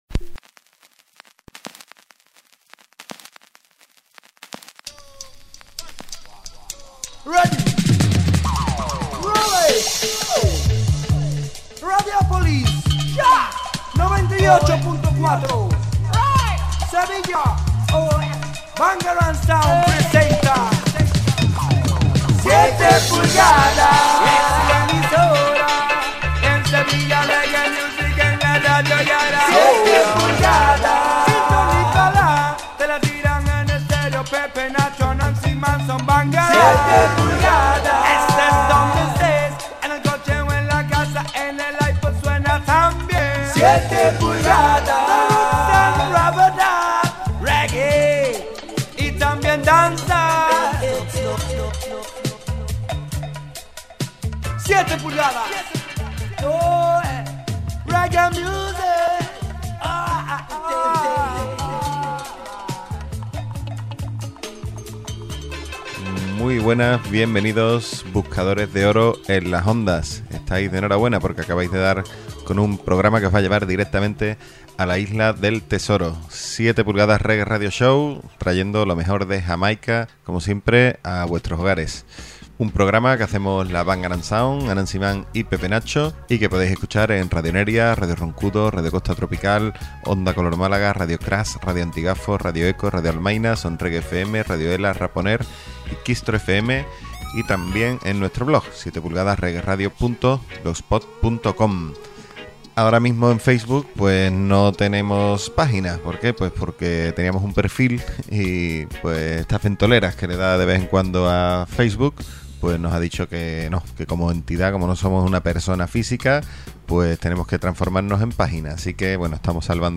Esta semana os traemos una esquita mixtape.